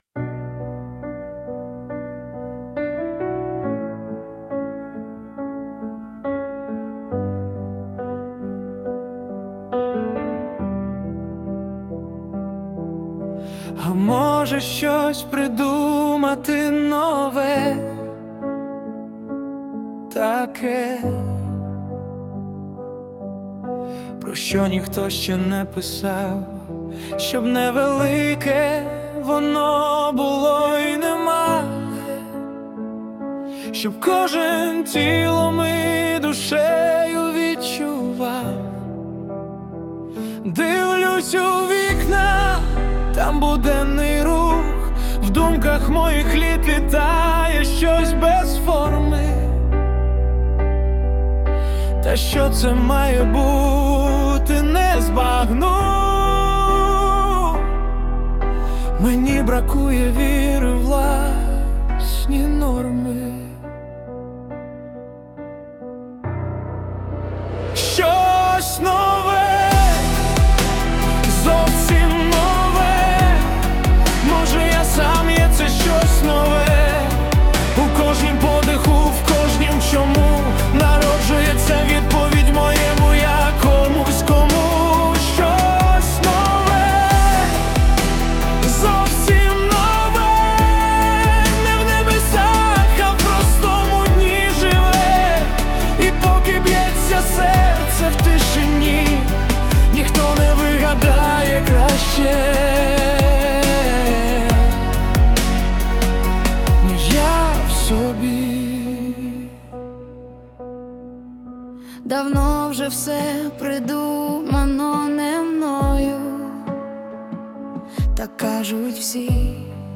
Прослухати музичні твори на вірші автора в обробці штучного інтелекту